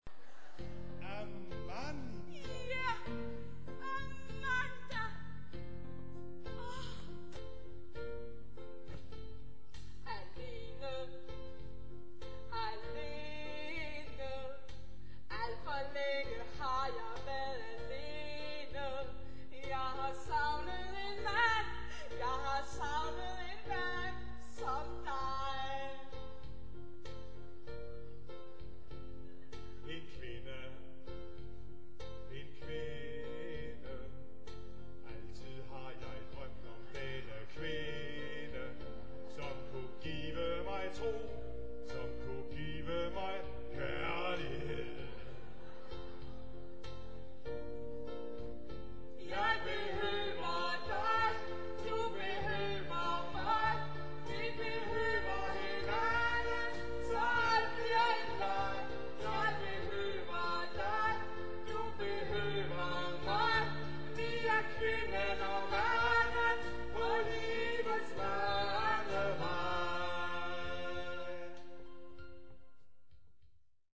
"live on stage!"